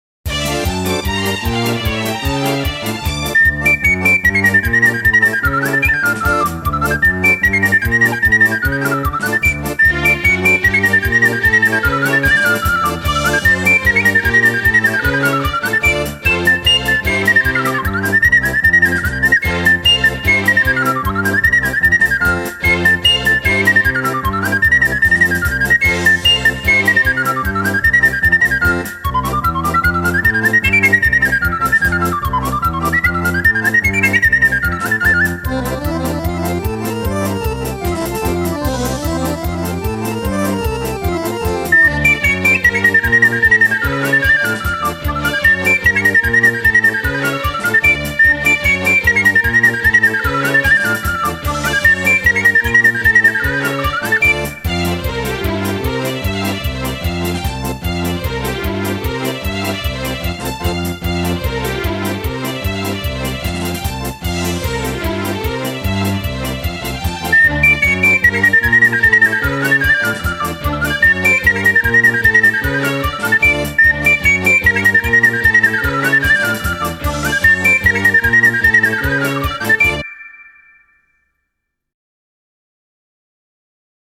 HOCH VIRTUOS BIS SCHWÄRMERISCH ROMANTISCH
Packende Tempi und virtuose Tonkaskaden (Darbietungen) reißen dabei das Publikum mit.
Hörprobe 1 (Panflöte) :